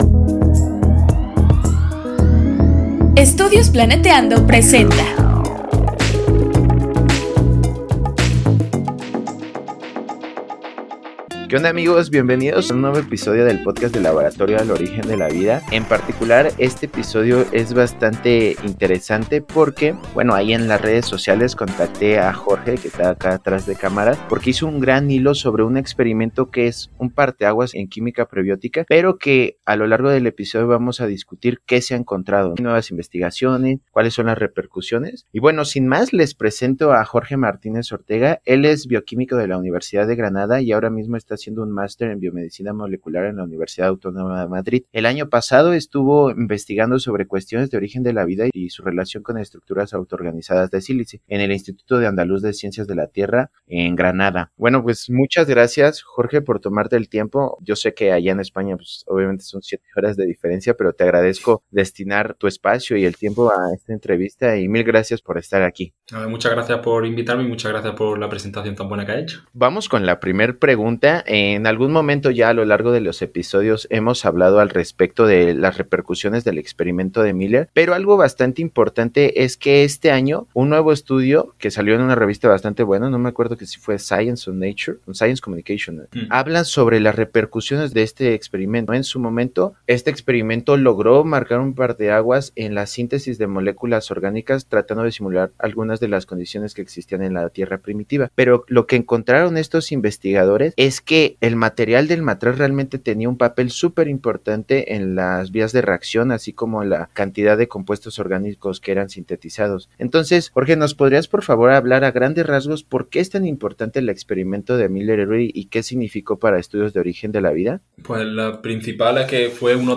Este año, un equipo de científicos encontró que el material del matraz donde se llevaron a cabo los experimentos tenia un papel crucial en la formación de moléculas orgánicas. Entrevista